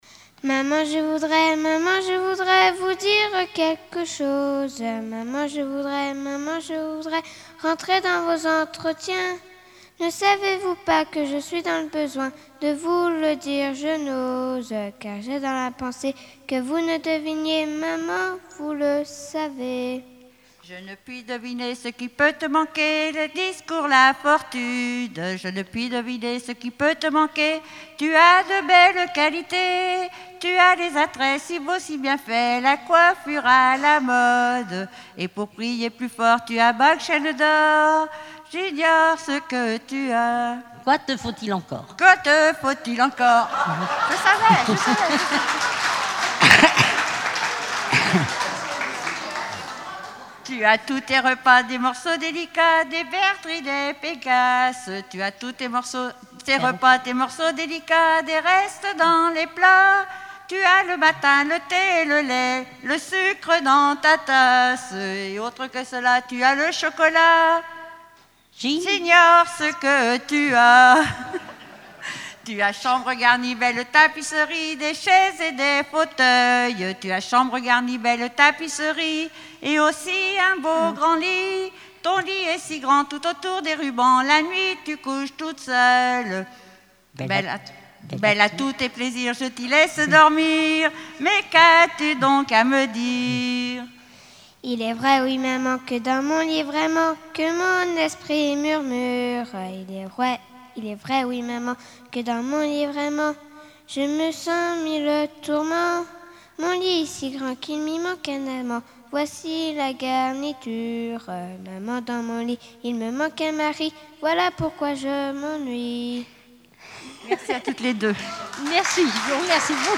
Dialogue mère-fille
Festival de la chanson traditionnelle - chanteurs des cantons de Vendée